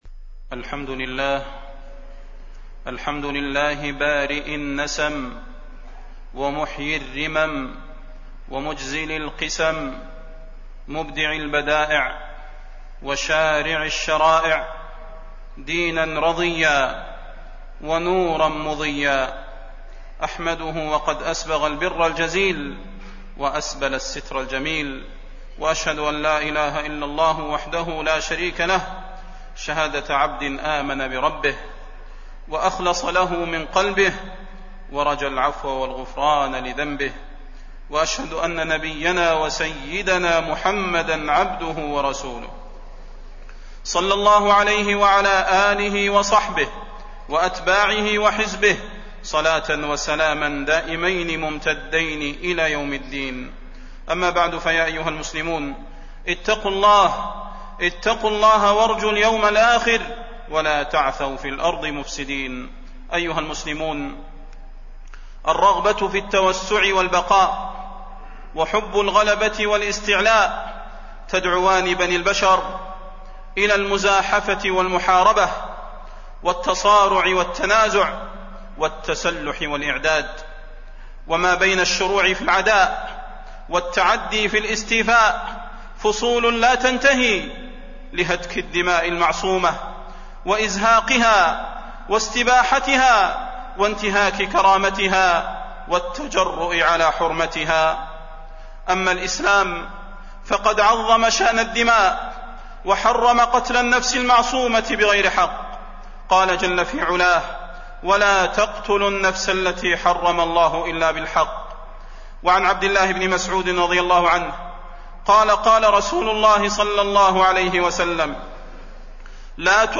تاريخ النشر ٣ صفر ١٤٣٢ هـ المكان: المسجد النبوي الشيخ: فضيلة الشيخ د. صلاح بن محمد البدير فضيلة الشيخ د. صلاح بن محمد البدير حرمة الدماء المعصومة